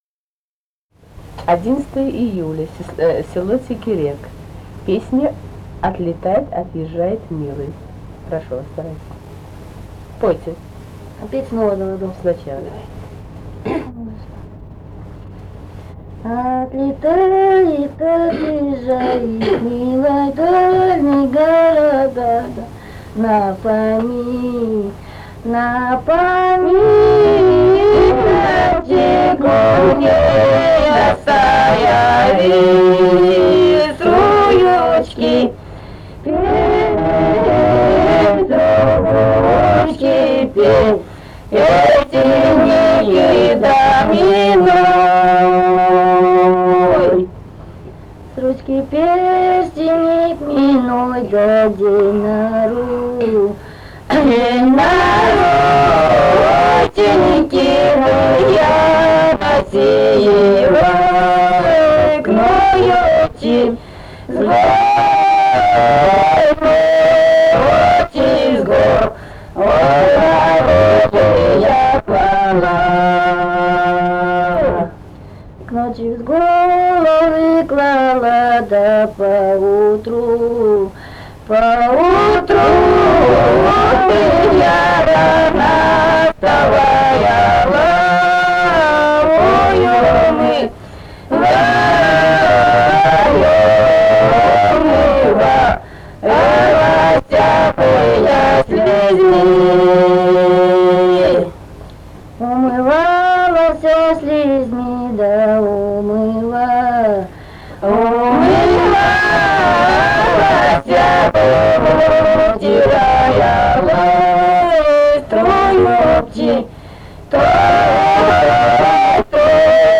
полевые материалы
Алтайский край, с. Тигирек Краснощёковского района, 1967 г. И1018-16